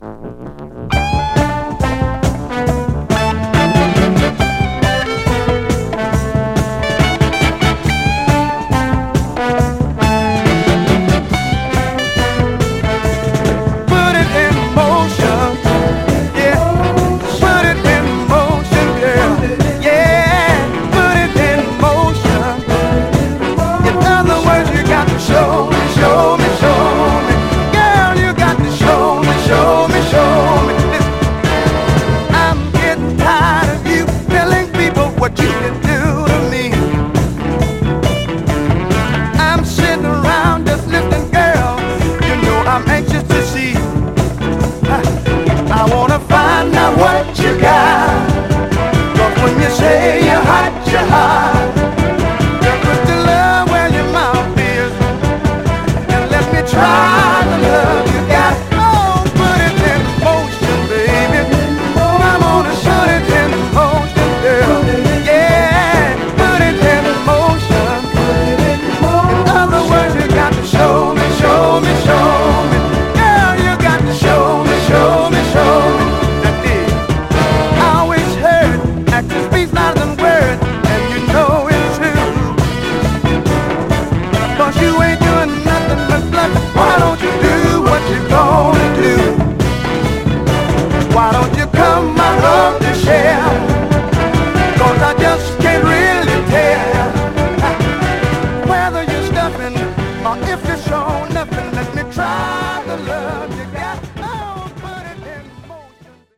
盤は細かい表面スレ、いくつか細かいヘアーラインキズありますが、音への影響は少なくプレイ良好です。
※試聴音源は実際にお送りする商品から録音したものです※